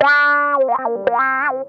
ITCH LICK 8.wav